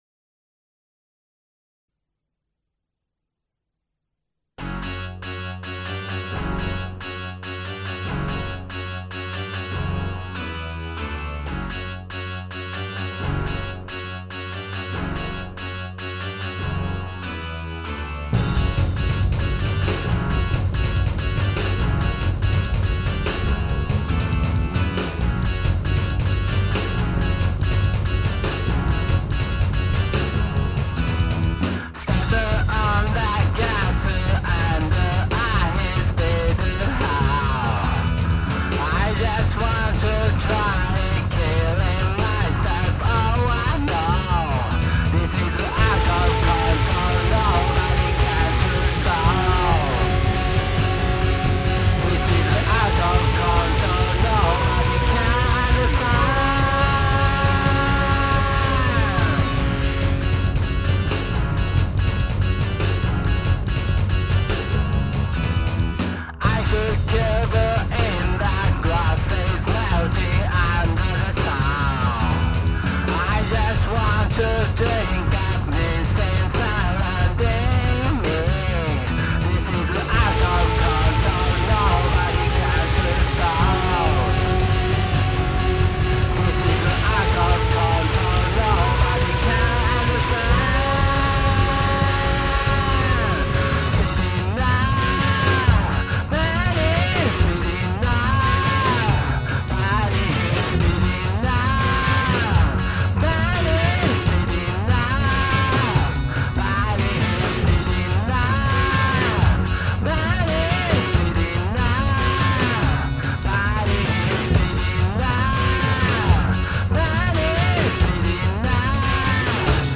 暗闇を疾走していくインダストリアルダンスチューン。